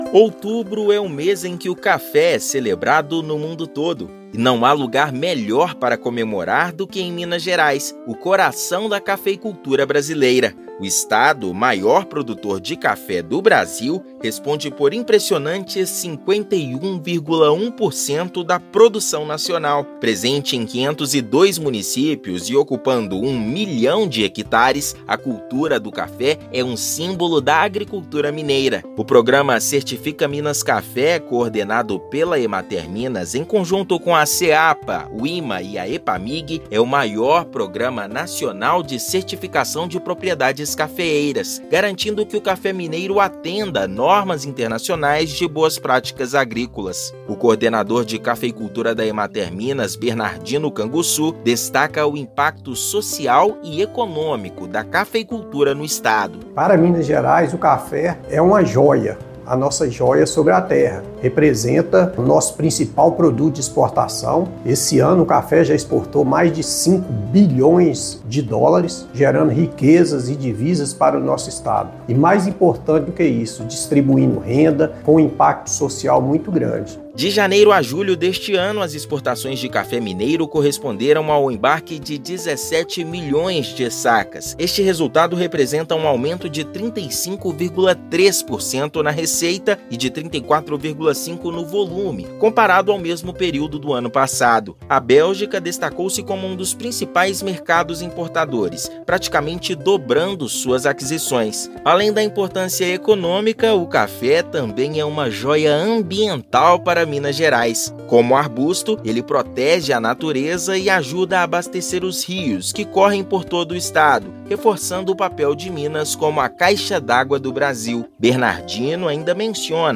Data é comemorada nesta terça-feira (1/10), momento em que o estado supera US$ 5 bilhões em exportações. Ouça matéria de rádio.